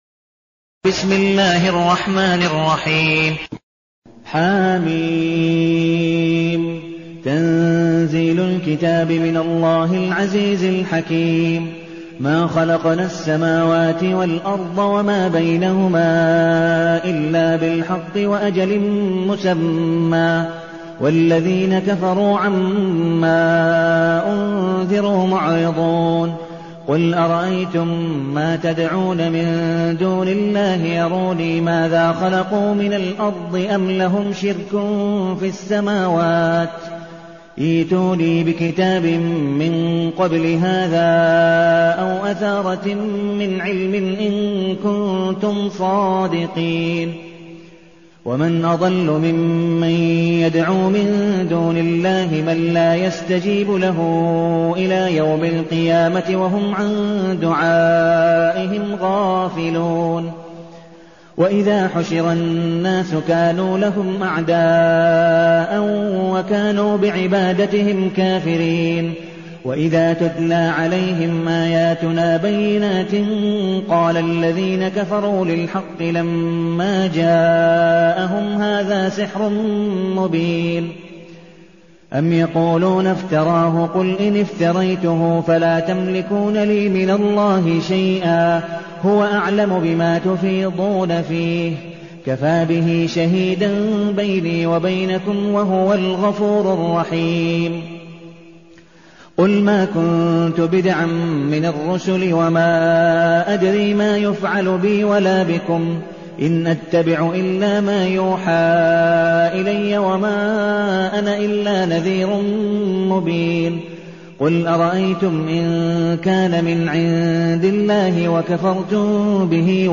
المكان: المسجد النبوي الشيخ: عبدالودود بن مقبول حنيف عبدالودود بن مقبول حنيف الأحقاف